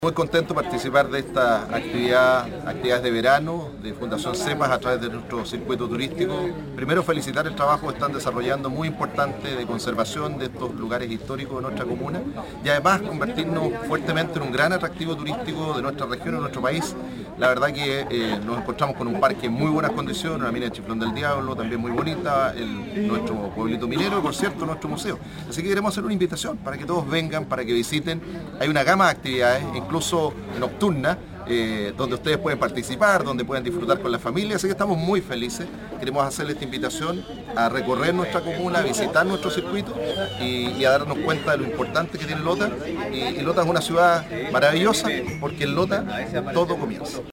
El alcalde de Lota, Jaime Vásquez, destacó la parrilla programática de verano que lleva adelante Fundación Cepas.